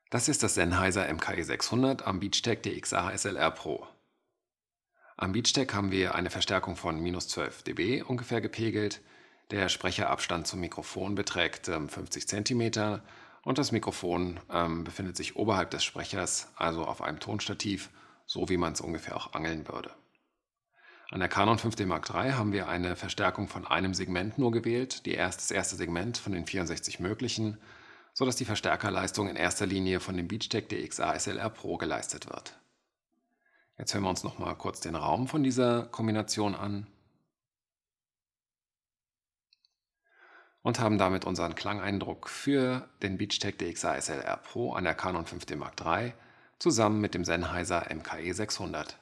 Und hier die Audio-Files mit einem leichten EQ und stärkerer Rauschunterdrückung:
Sennheiser MKE 600 via Beachtek DXA-SLR PRO an Canon EOS 5D Mark III + EQ + DeNoise